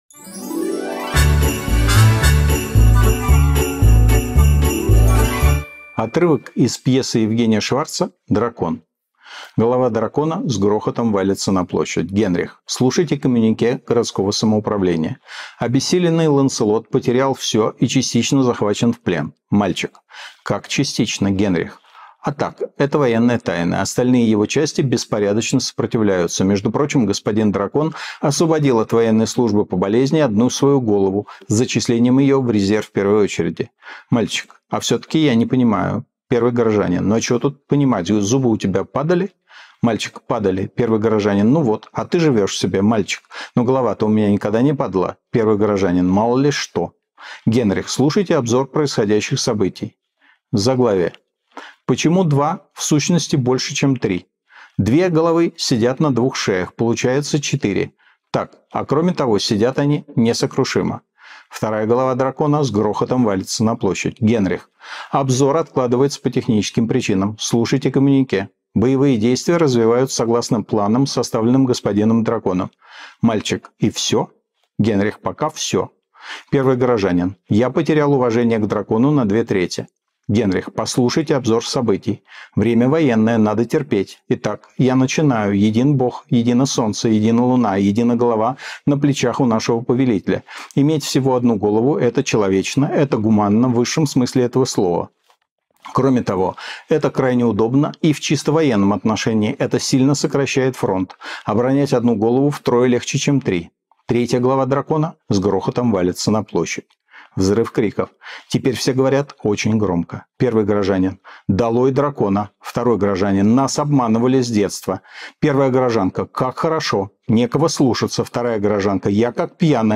Читает Михаил Ходорковский
Михаил Ходорковский читает «Дракона» Евгения Шварца